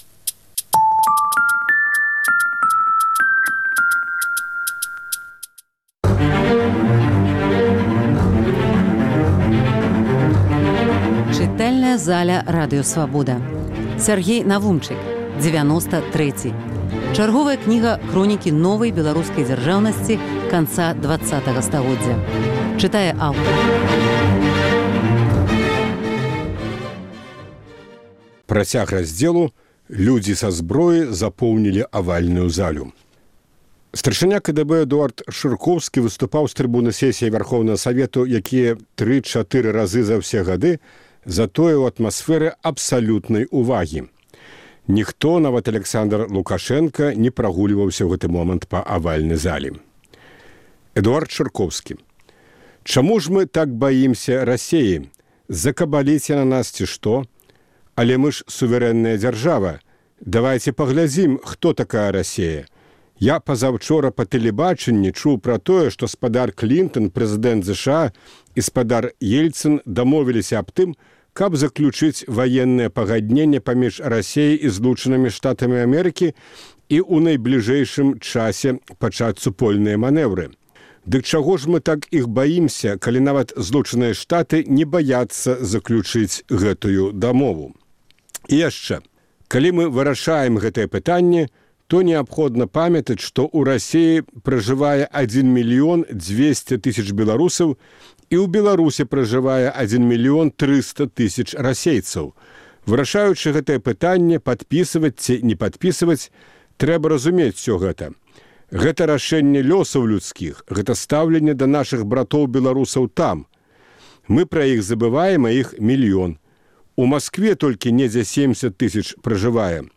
Фрагмэнты кнігі Сяргея Навумчыка "Дзевяноста трэці". Чытае аўтар.